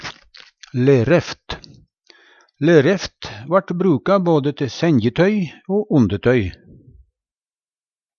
lereft - Numedalsmål (en-US)